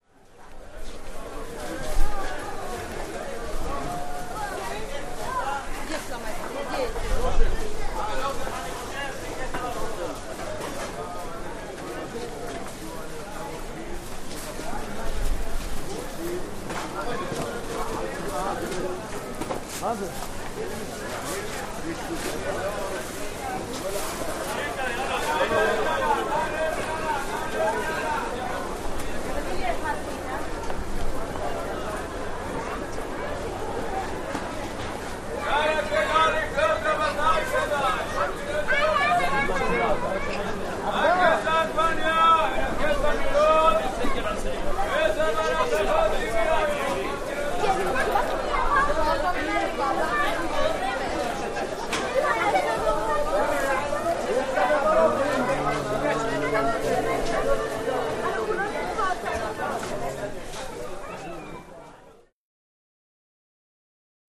Israeli Market Place, Kids And Sales Man, Merchant Foley